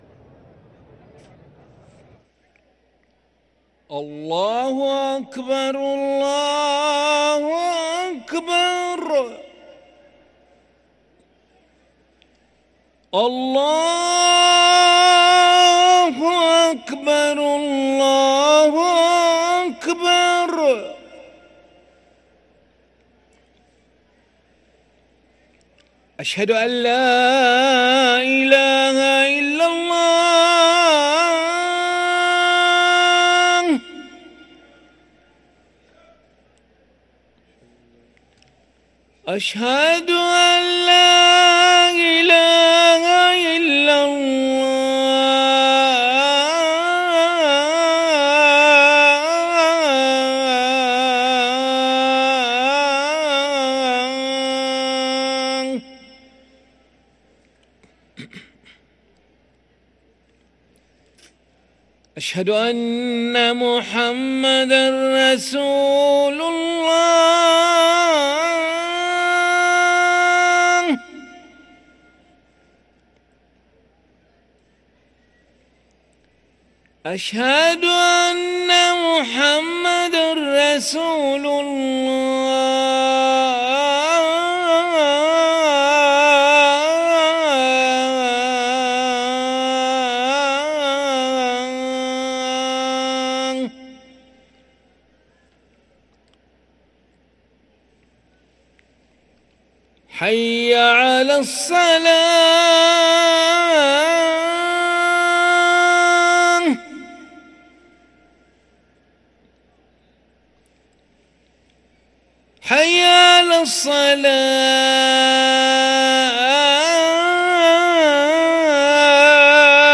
أذان العشاء للمؤذن علي ملا الأحد 6 شعبان 1444هـ > ١٤٤٤ 🕋 > ركن الأذان 🕋 > المزيد - تلاوات الحرمين